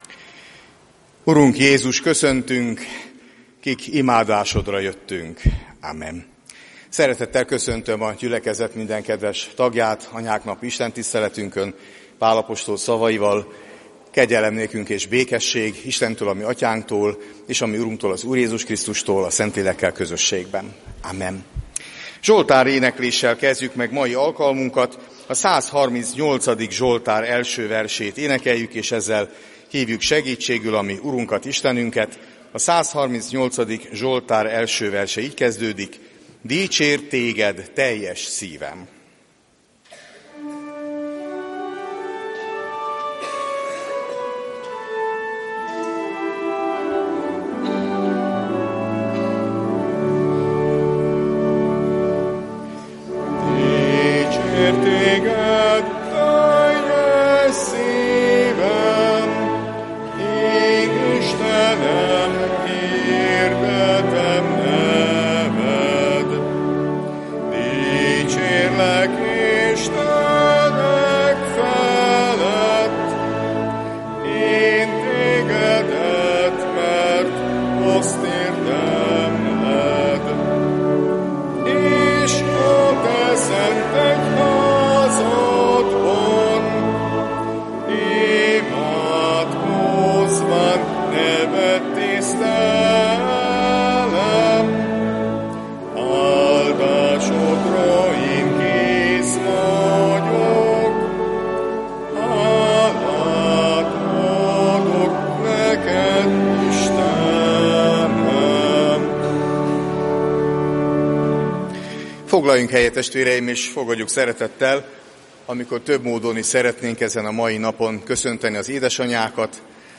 (2.Korinthus 5,2) Anyák napi családi istentisztelet óvodánk Csengettyű csoportjának szolgálatával Letöltés Letöltés